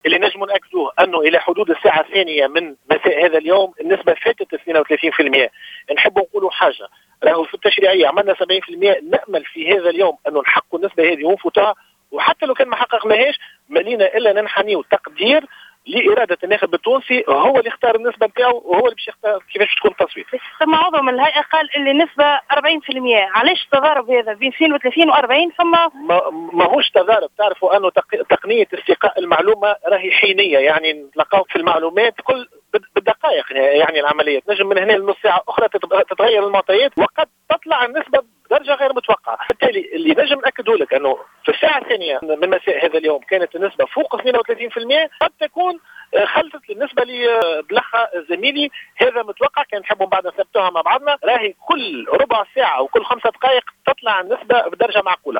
أكد عضو الهيئة العليا المستقلة للانتخابات نبيل بفون في تصريح لجوهرة "اف ام" ان النسبة العامة للمشاركة في التصويت لانتخاب رئيس للجمهورية فاقت إلى حدود الساعة الثانية بعد الظهر نسبة 32 بالمائة.